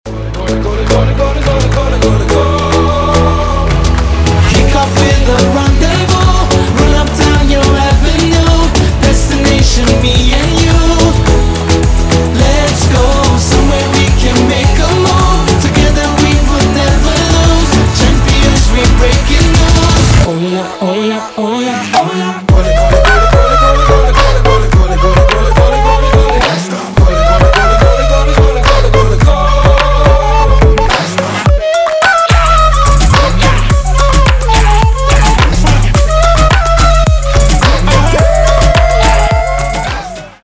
• Качество: 320, Stereo
восточные мотивы
зажигательные
заводные
dance
Moombahton